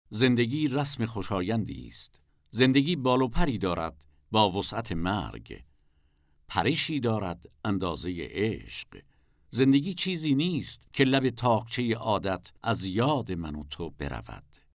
برای گوینده ای که امروز خاموش شد + نمونه صدا
صدای باصلابت و گیرا و دلچسبی داشتند.